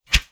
Close Combat Swing Sound 30.wav